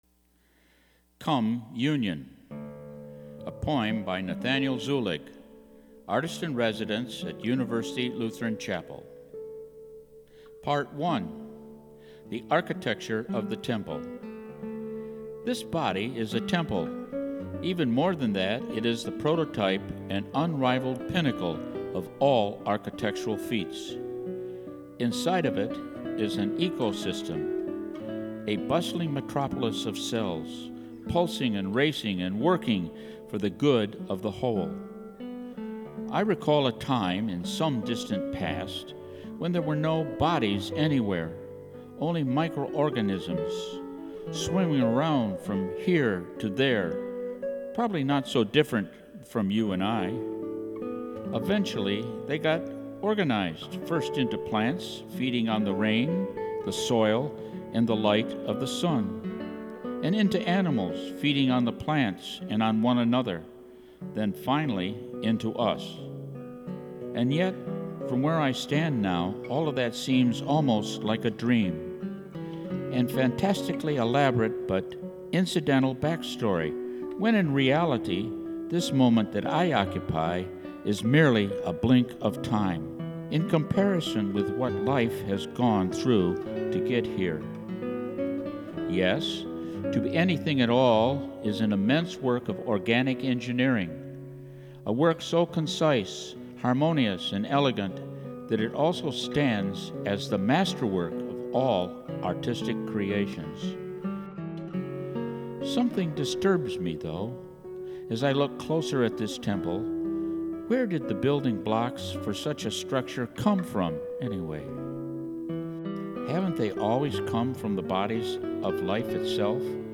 Poems